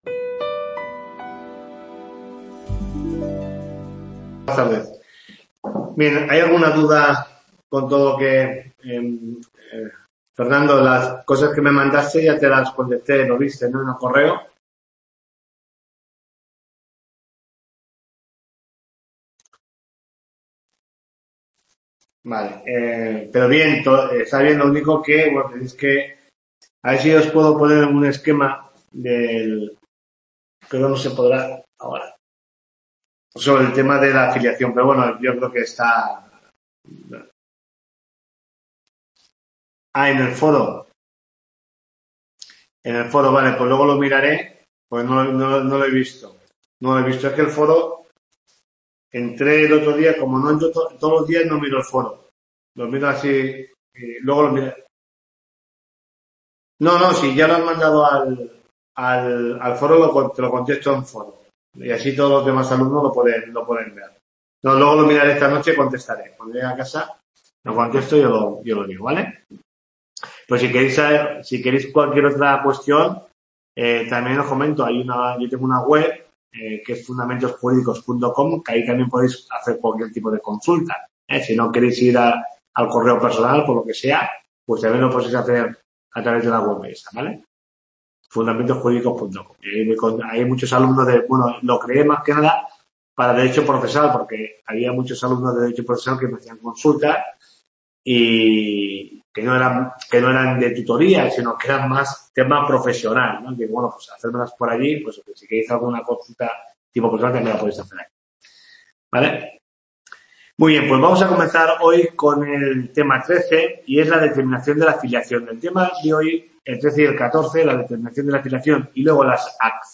TUTORIA 8